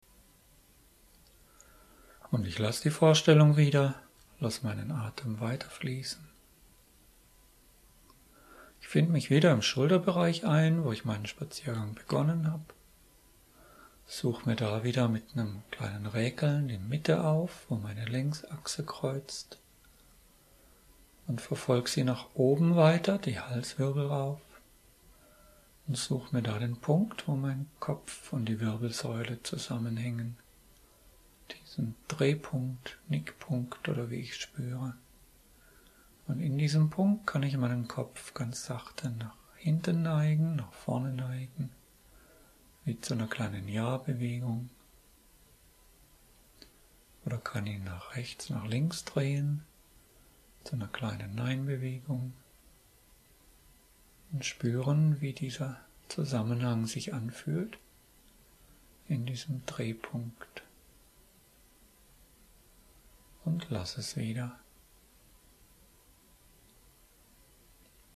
Dabei können Sie sich von meiner Stimme begleiten lassen.
Dort hören Sie sich die Anleitung an und folgen ihr auf die Weise, die Ihnen im Moment entspricht.